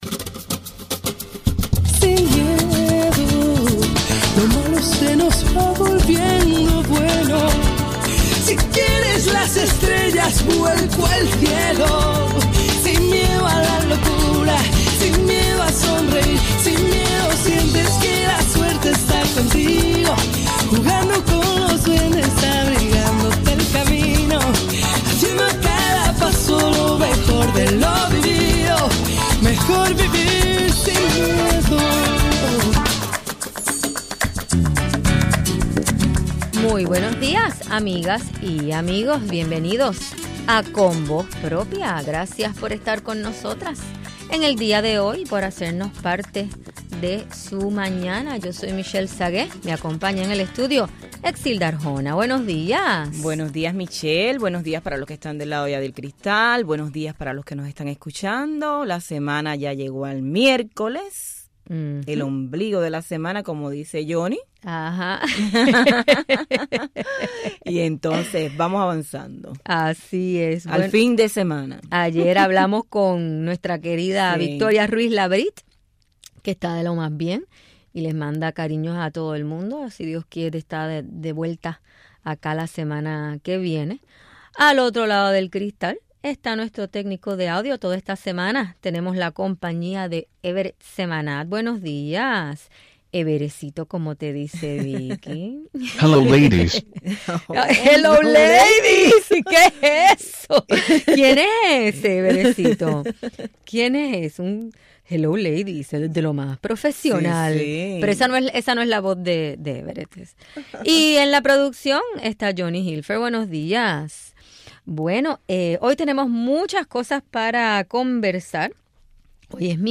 Entrevistamos a la escritora